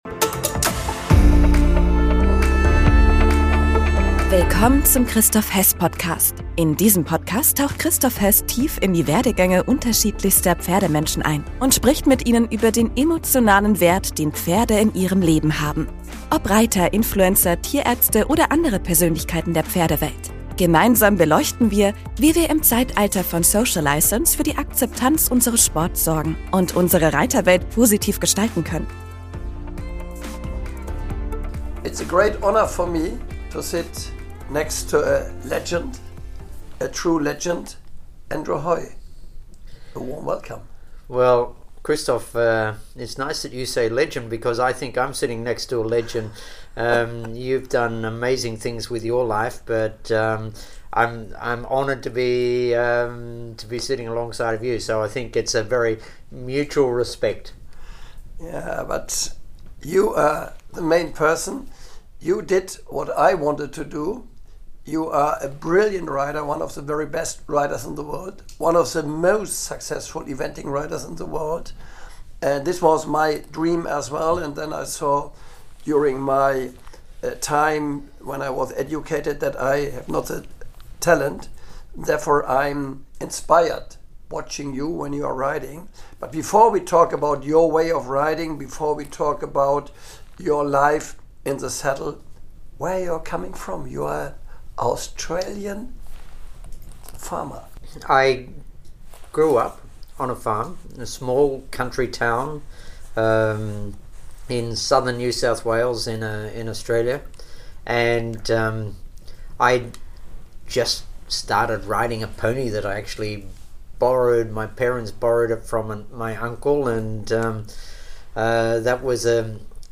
Andrew’s dedication, precision, and passion for the sport make this conversation a must-listen for every equestrian enthusiast!